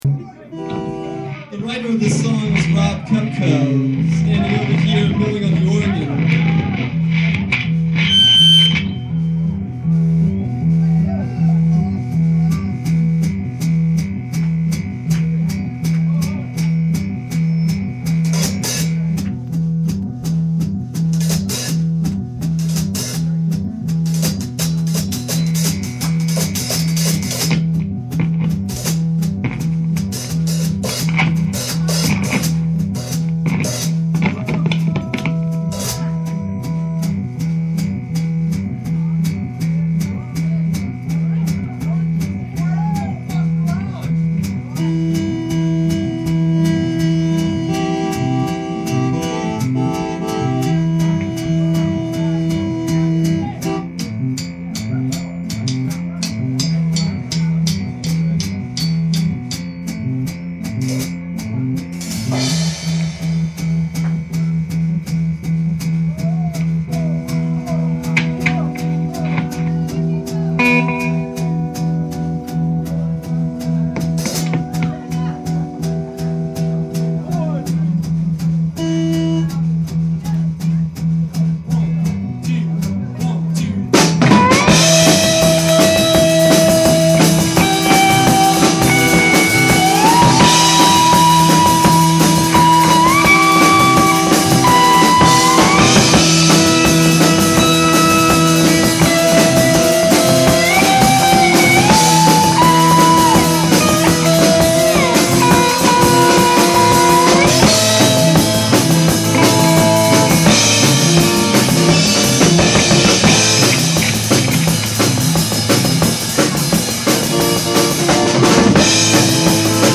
percussion
keyboards
guitar, vocals